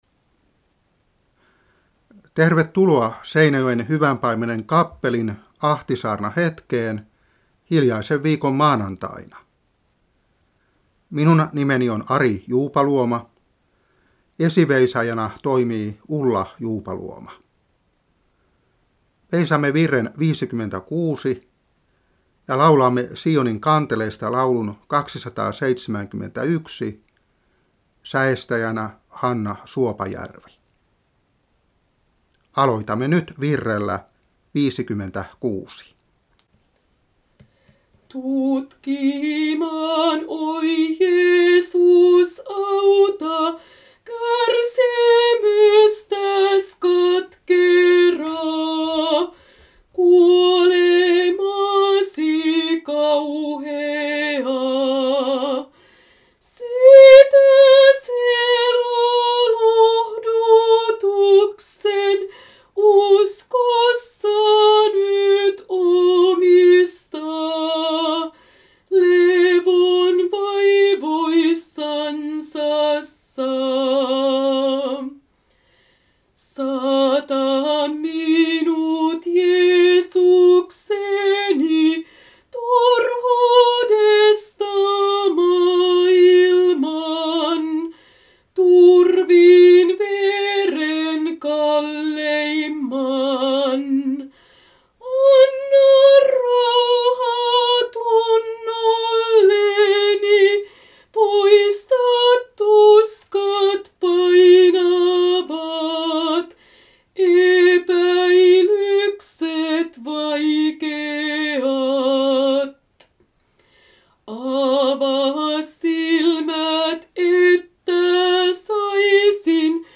Saarna 1994-3.